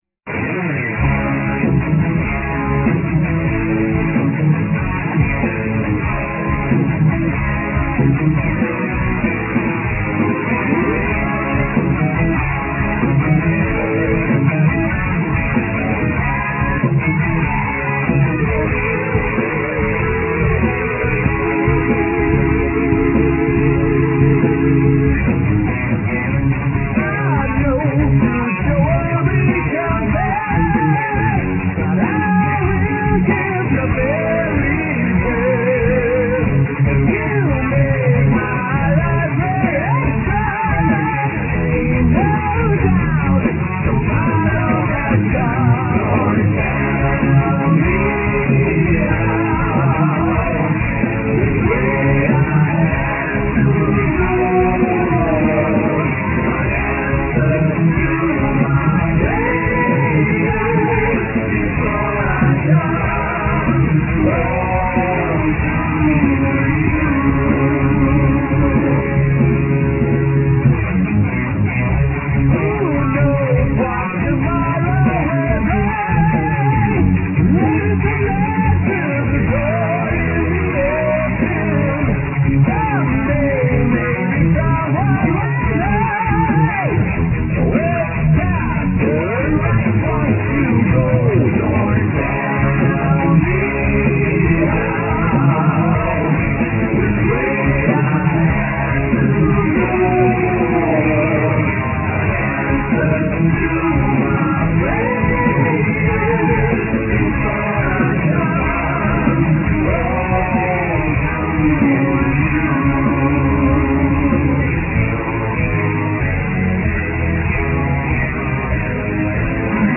Vocals, Guitars and Sitars
Bass
Drums
Keyboards